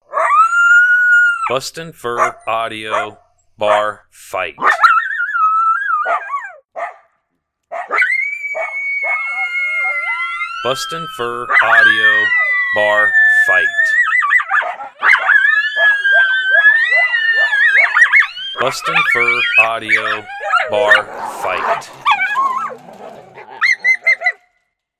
Bar Fight is a mixture of a pair of Coyotes aggressively howling, mixing in a 3 Coyote Howling and eventually leads into a show of dominance at the end.